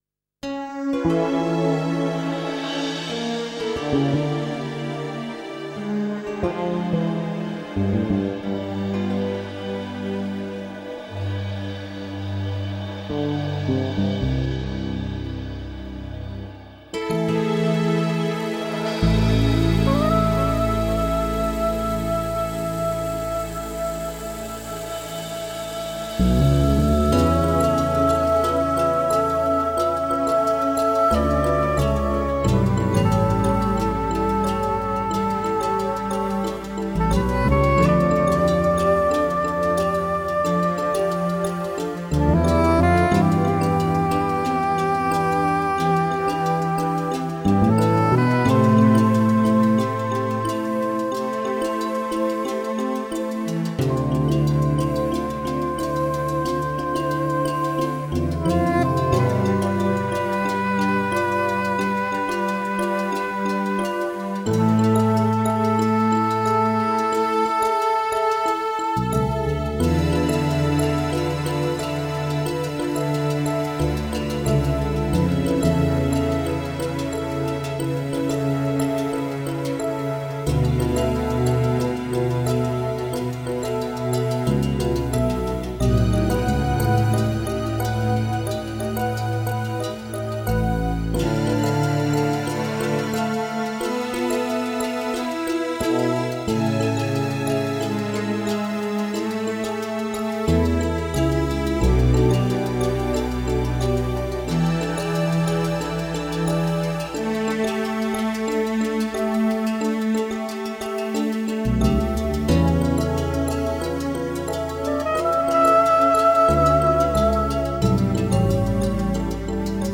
minimalistic lyrical content
sensuous melodies and hypnotic rhythm patterns